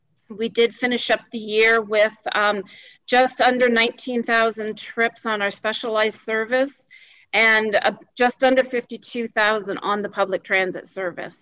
Quinte Access Transit provided an update on its ongoing rebranding efforts at Tuesday’s Quinte West Council meeting.